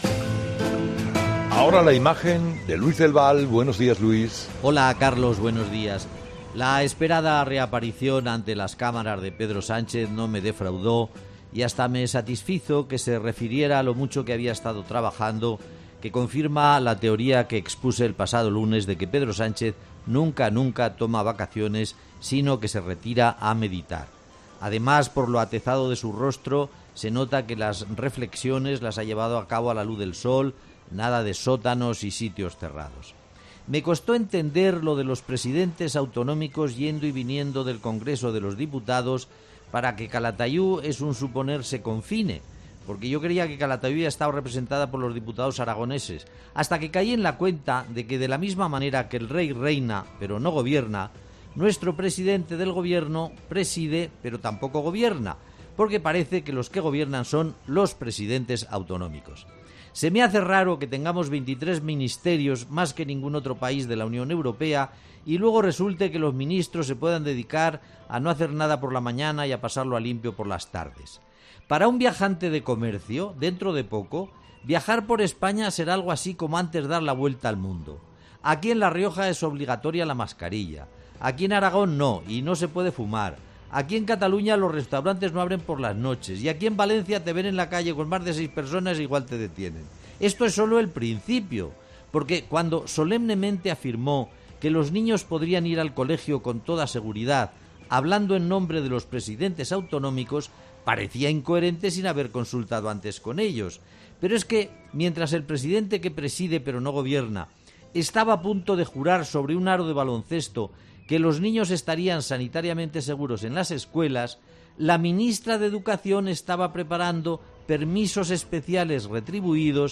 El colaborador de 'Herrera en COPE' analiza la declaración institucional del presidente del Gobierno